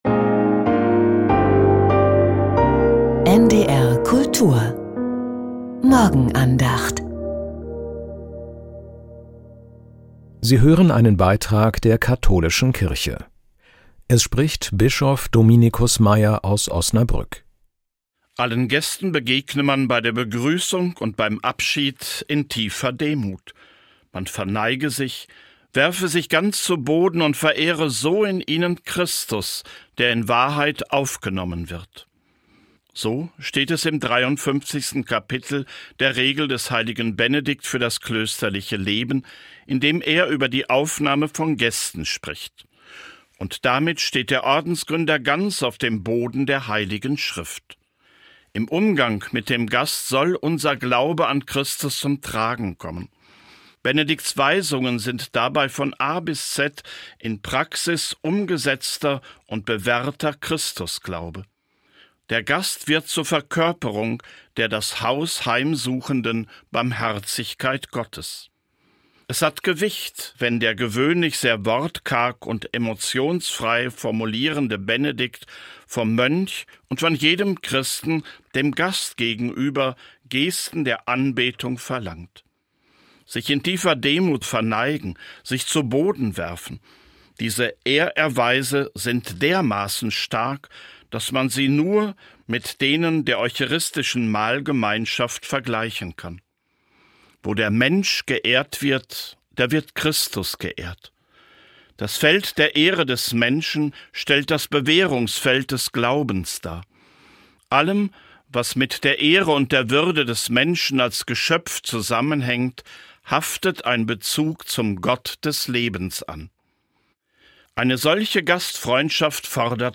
Morgenandacht mit Bischof Dominicus Meier.